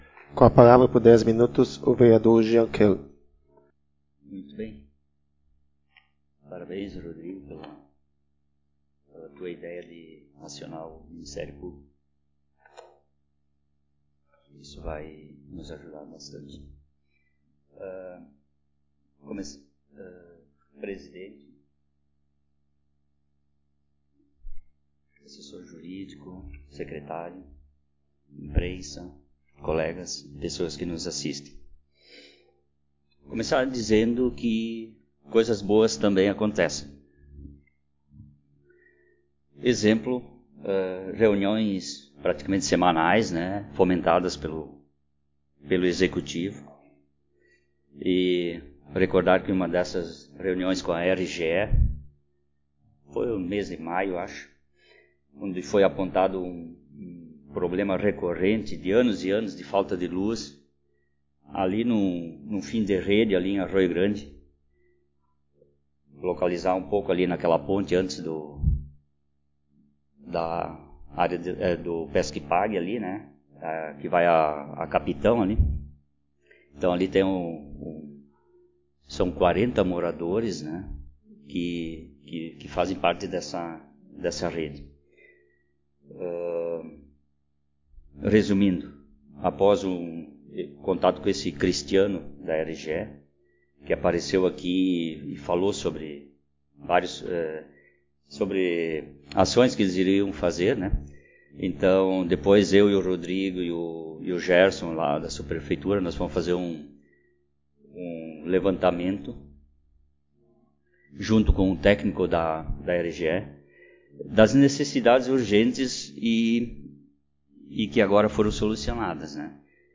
Modalidade: Áudio das Sessões Vereadores